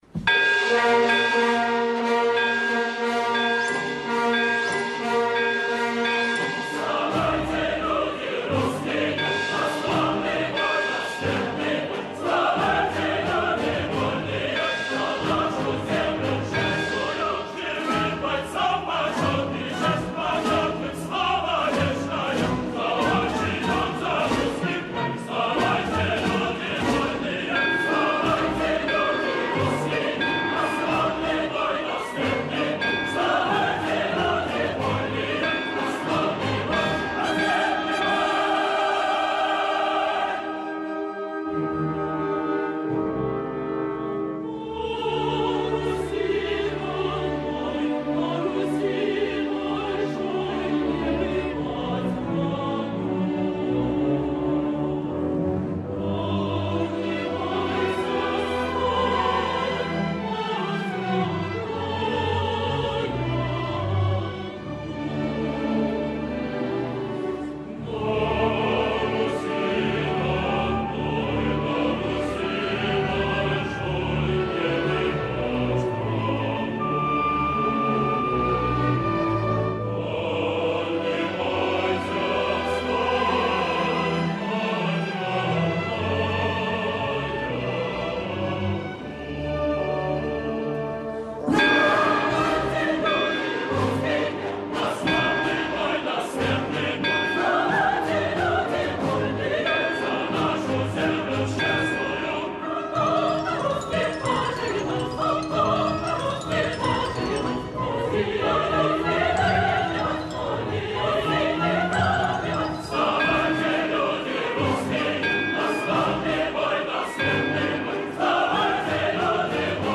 Кантата
Хор